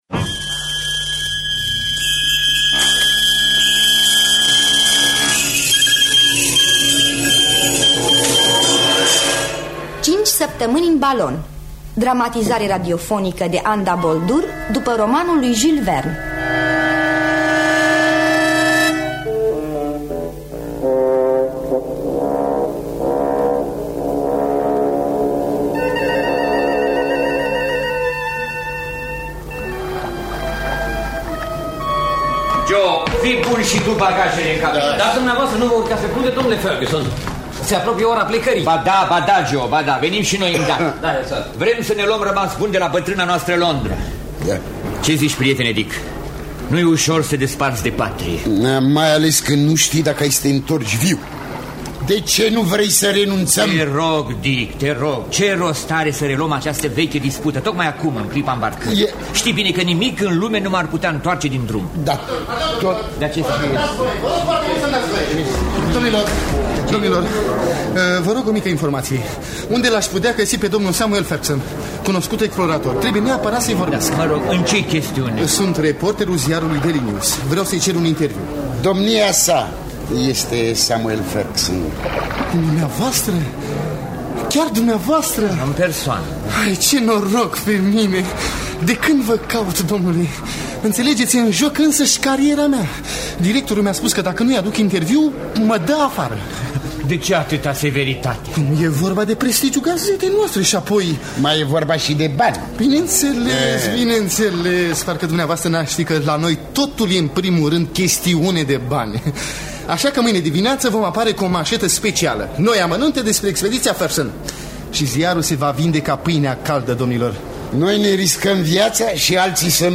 Cinci săptămâni în balon de Jules Verne – Teatru Radiofonic Online